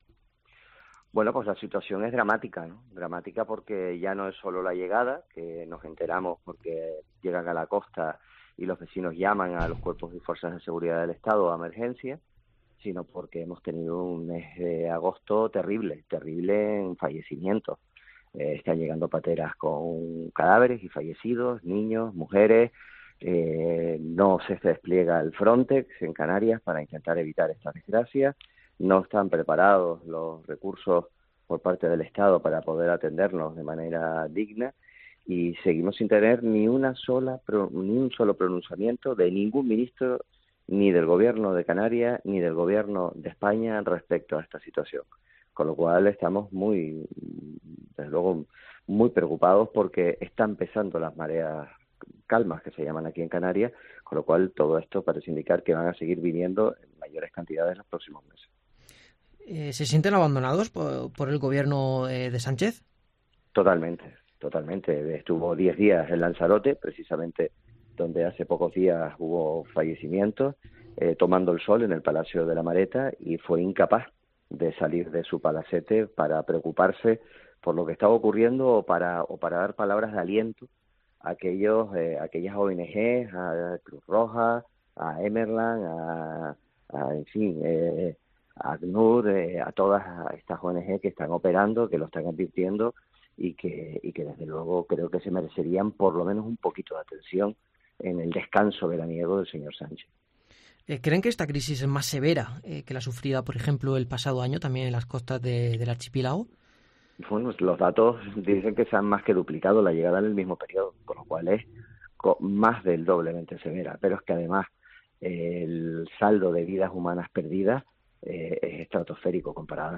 Por su parte, el expresidente del Gobierno de Canarias y actual secretario general nacional de Coalición Canaria-PNC, Fernando Clavijo, ha declarado en los micrófonos de COPE que la situación “es dramática” porque durante el mes de agosto “hemos recibido multitud de pateras con cadáveres de niños, mujeres y hombres”.